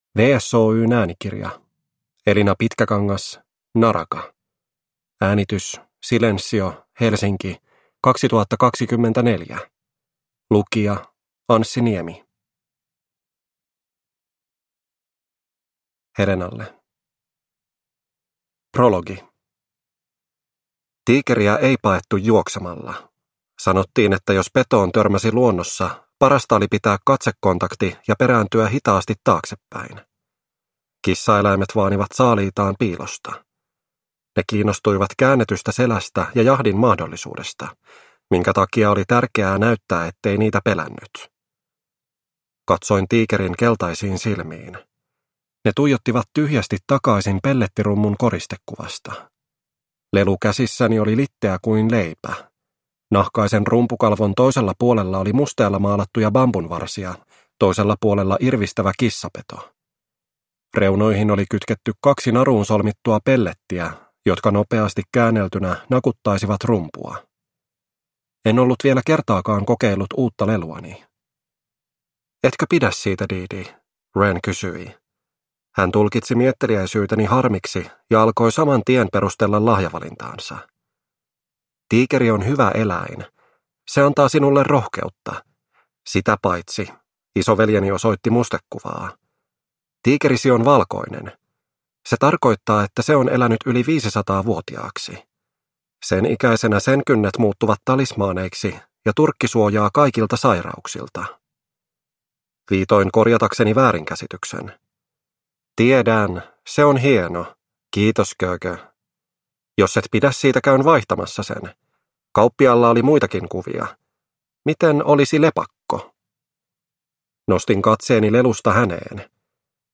Naraka – Ljudbok